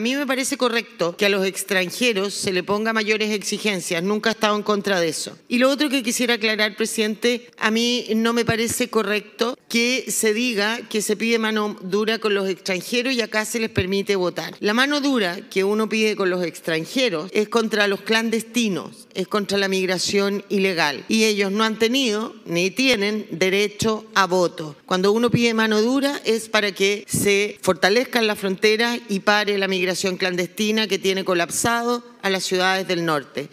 La senadora por Tarapacá, Luz Ebensperger, respaldó la iniciativa, pero insistió en diferenciar entre migración regular e irregular.